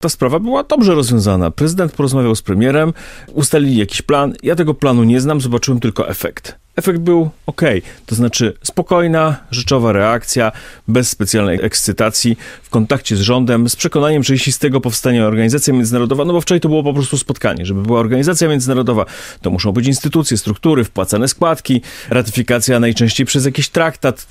Na antenie Programu 1 Polskiego Radia szef sejmowej komisji spraw zagranicznych Paweł Kowal pochwalił współpracę między prezydentem a premierem w kwestii członkostwa Polski w Radzie Pokoju: